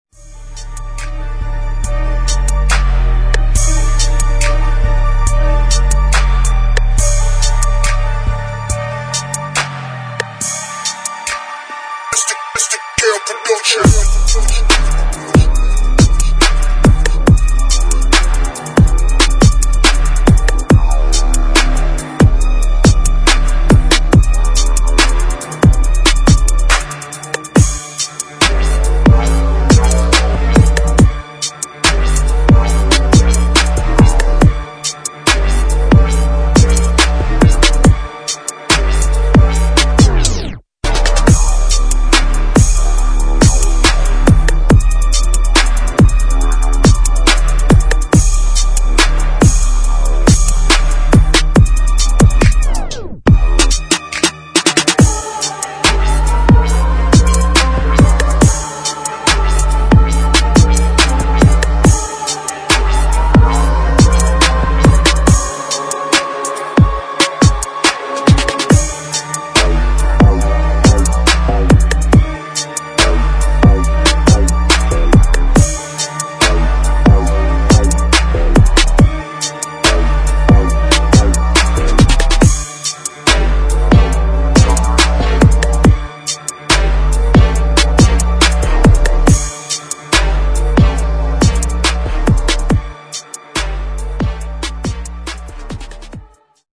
[ GRIME / DUBSTEP ]